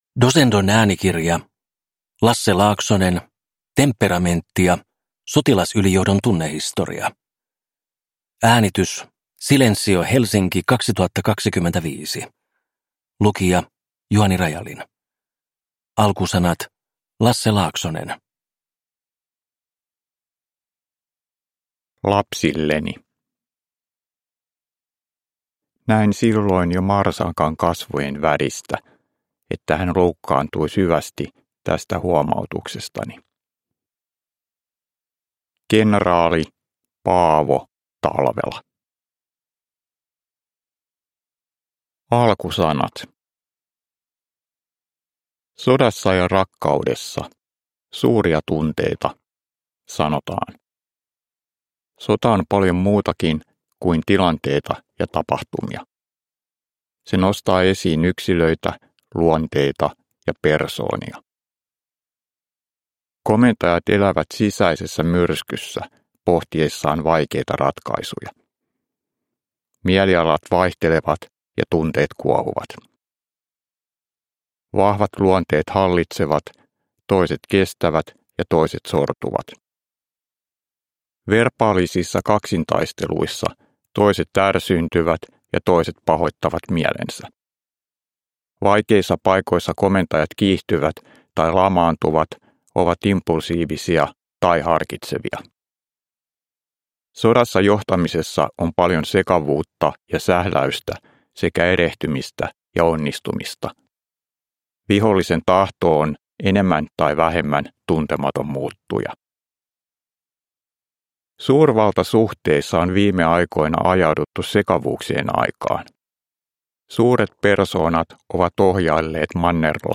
Temperamenttia! Sotilasylijohdon tunnehistoria – Ljudbok